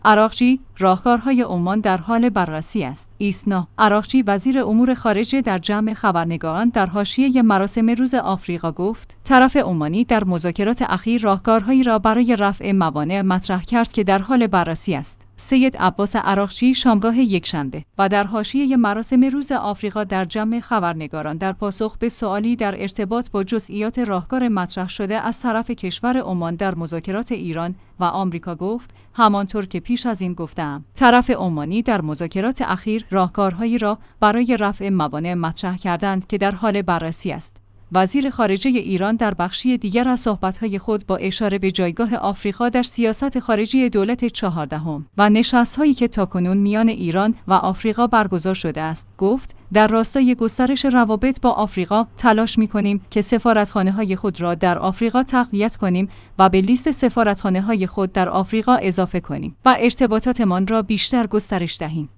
ایسنا/ عراقچی وزیر امور خارجه در جمع خبرنگاران در حاشیه مراسم روز آفریقا گفت: طرف عمانی در مذاکرات اخیر راهکارهایی را برای رفع موانع مطرح کرد که در حال بررسی است.